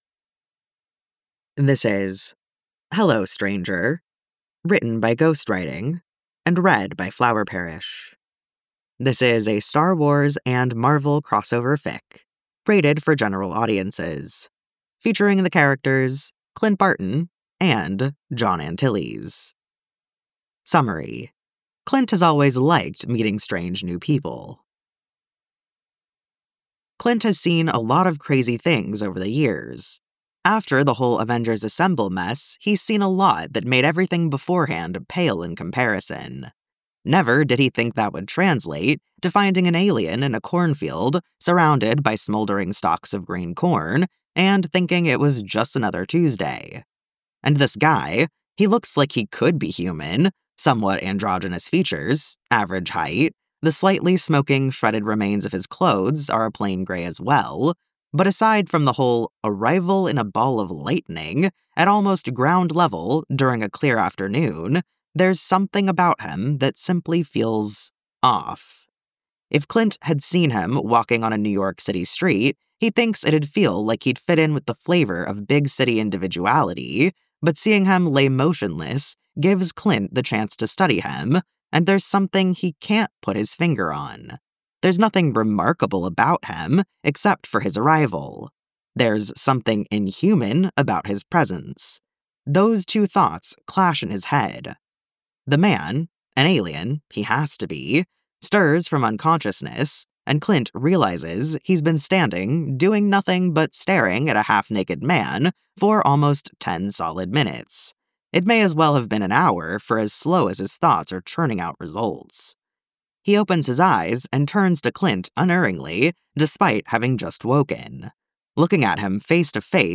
stream as a single mp3: with sfx no sfx